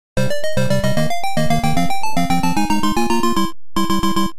Мелодия